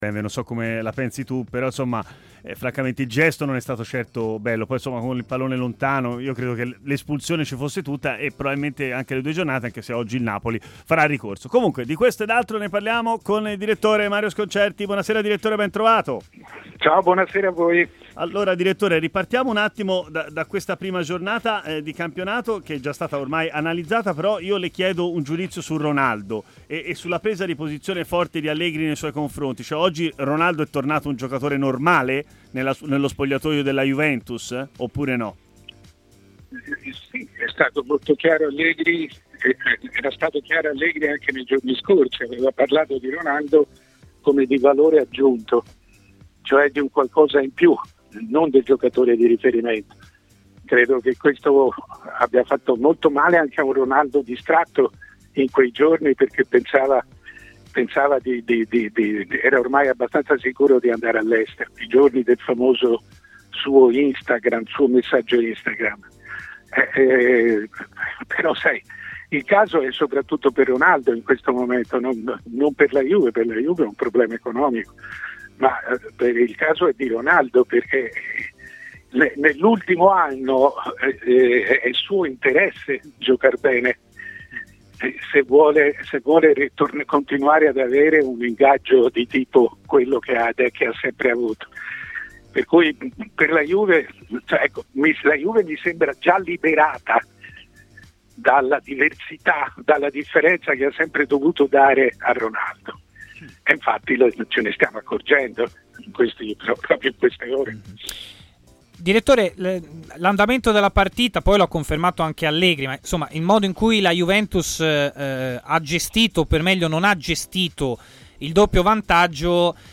ha così parlato in diretta a Stadio Aperto